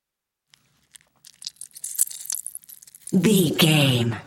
Creature eating flesh peel juicy
Sound Effects
scary
ominous
disturbing
horror